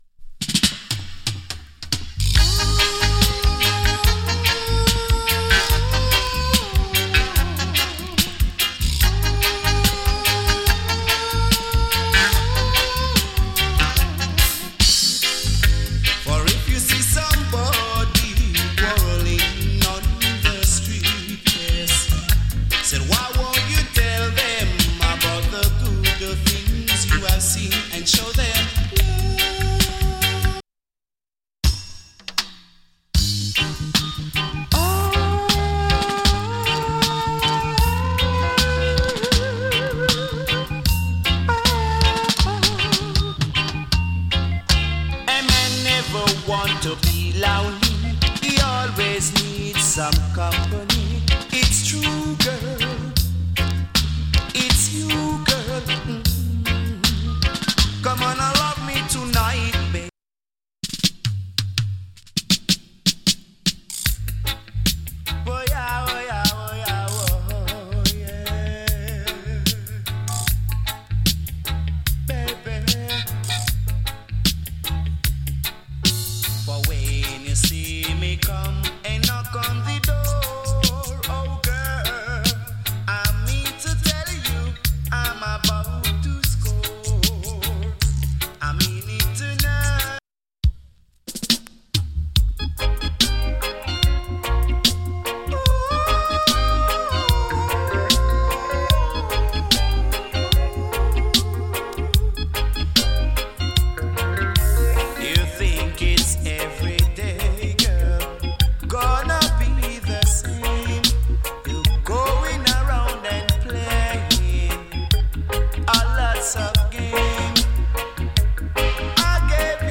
チリ、ジリノイズわずかに有り。